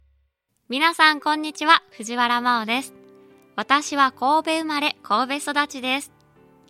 出⾝地・⽅⾔ 兵庫県神戸市・神戸弁、関西弁
ボイスサンプル